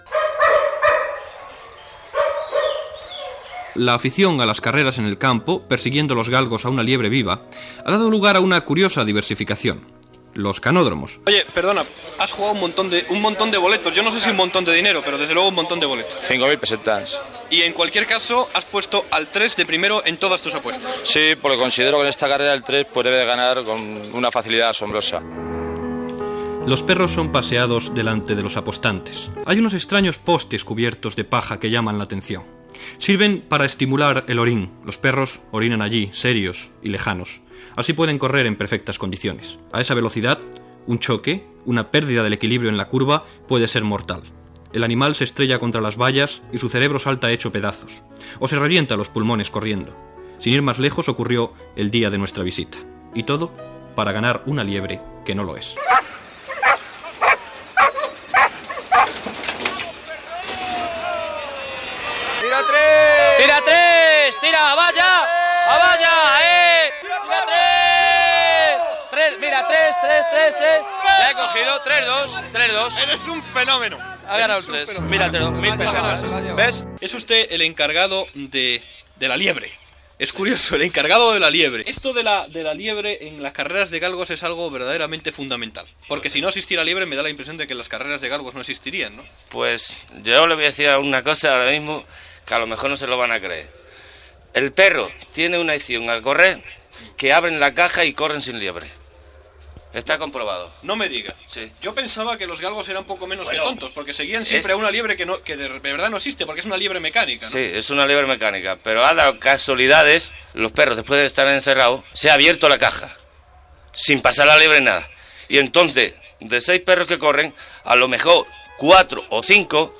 Reportatge "Un día en llas carreras", sobre les curses de llebrers, fet al canòdrom de Madrid
Informatiu